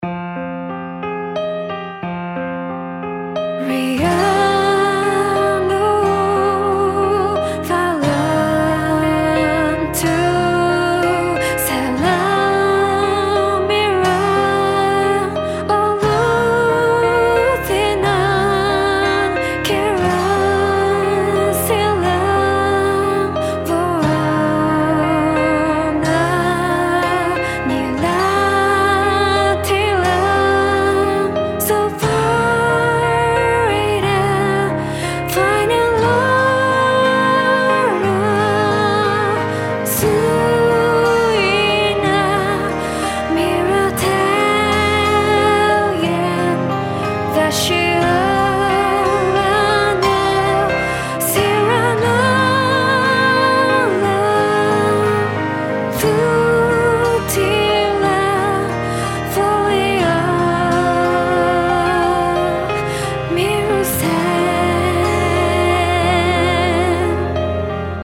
今回は哀愁漂う歌モノの曲をご用意しました。(音量にご注意ください。)
ボーカルは Synthsizer V2  宮舞モカで、歌詞は LLM で作った造語です。
この曲をボーカルとオケを別々に書き出しました。
sorrowmusic_total.mp3